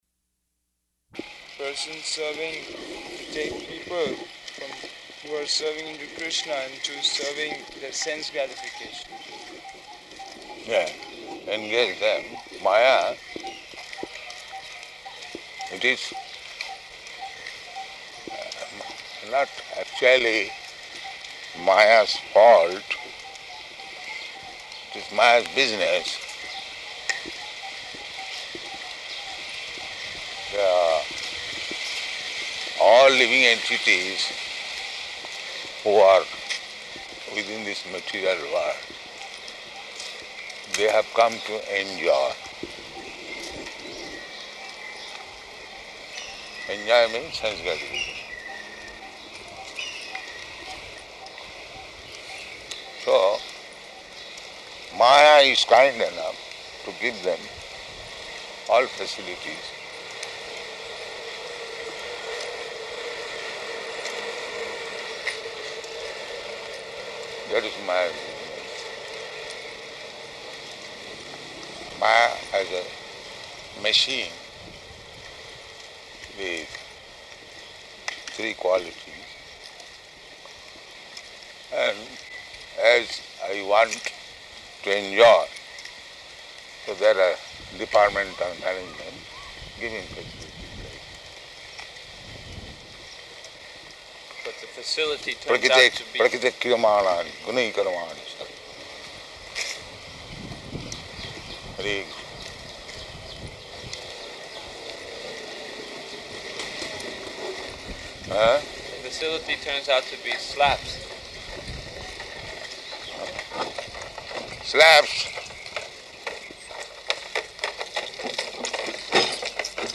Type: Walk
Location: Hyderabad